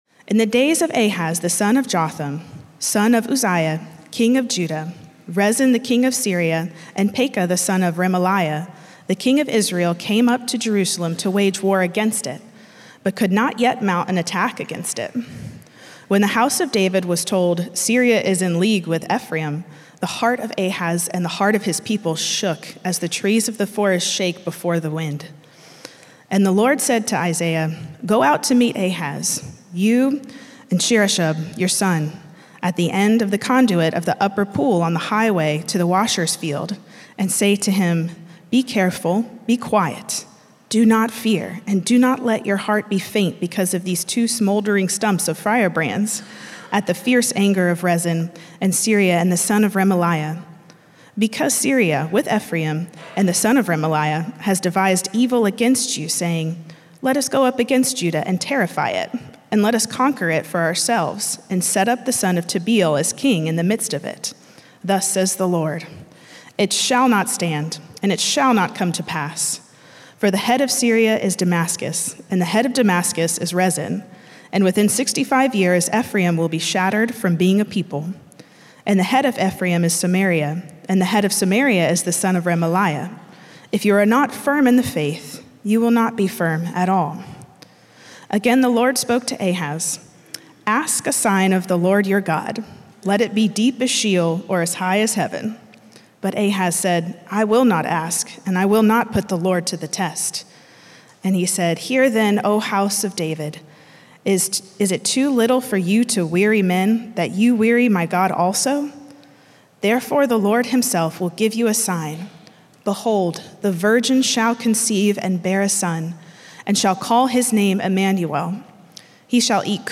A message from the series "Good News of Great Joy."